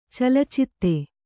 ♪ calacitte